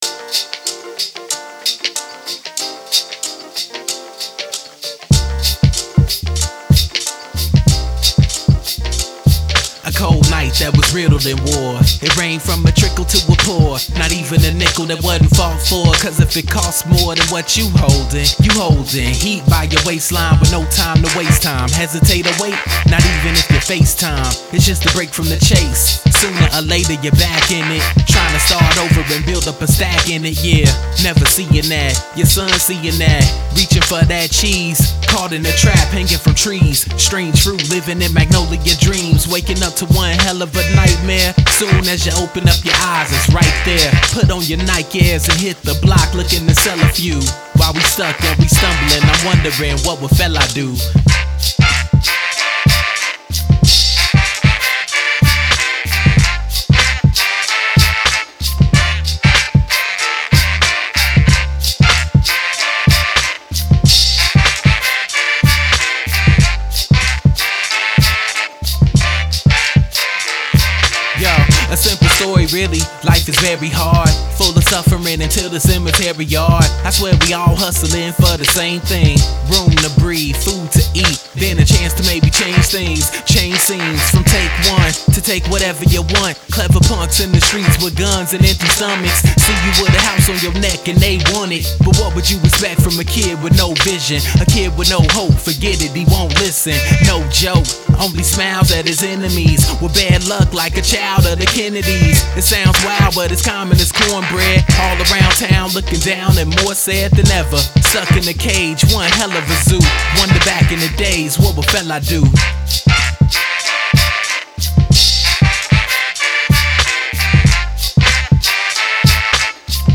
Chicago rapper
Amsterdam producer